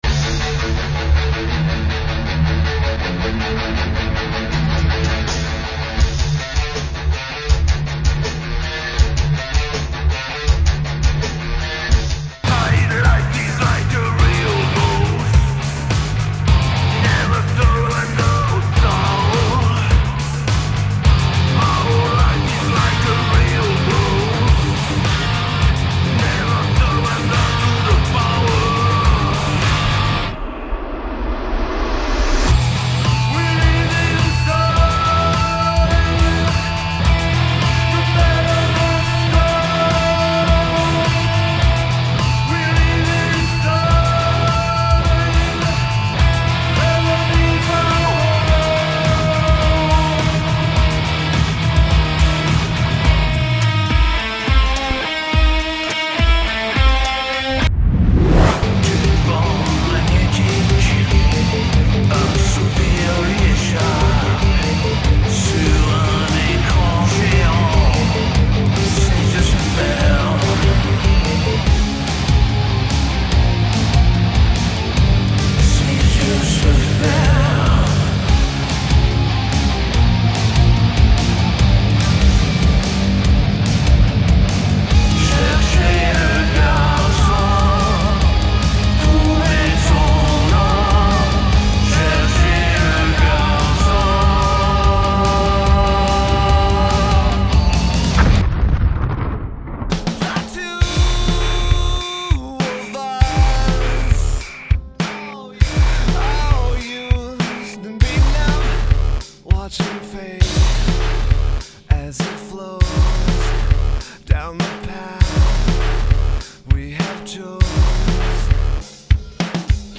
Qui a dit que le metal français n’existait pas ?